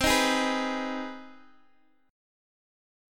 CmM7#5 chord